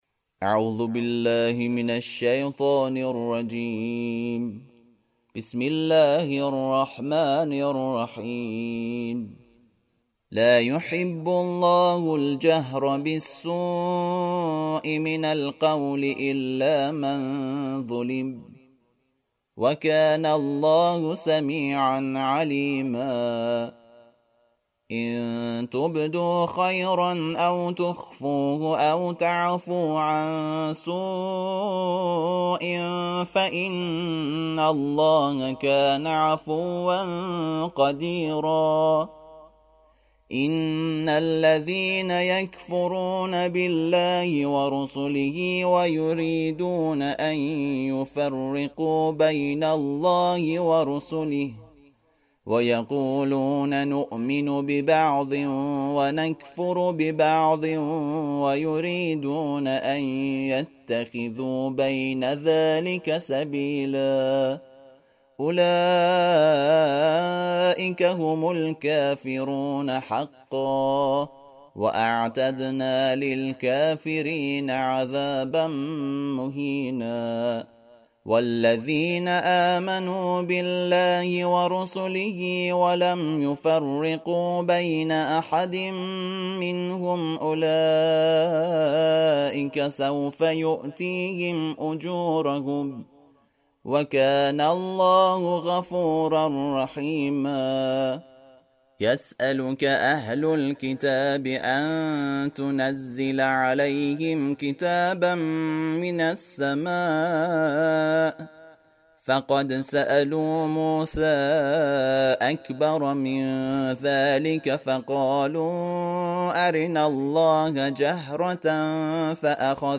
ترتیل صفحه ۱۰۲ سوره مبارکه نساء(جزء ششم)